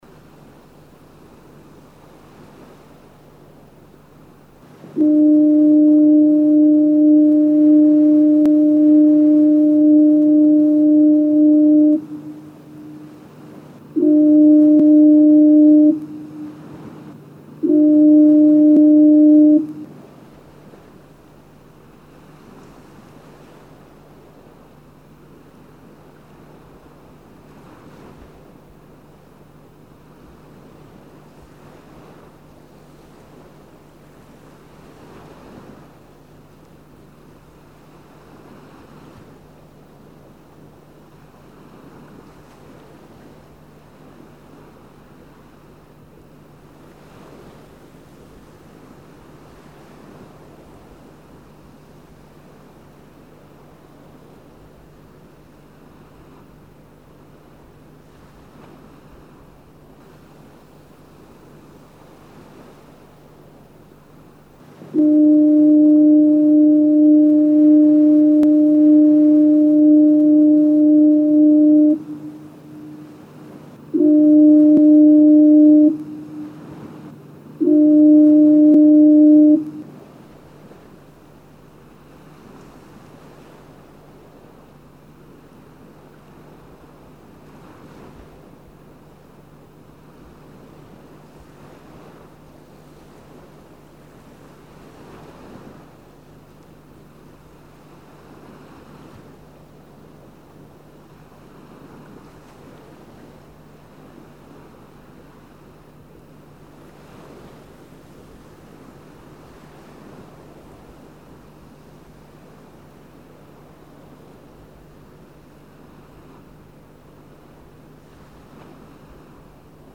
Lyssna på ljudfil) som kraftigt bölade som varning för sjöfarten att hålla ut från land.
Som första fyrplats i landet fick Hållö år 1926 en nautofon.
HalloNautofon-2.mp3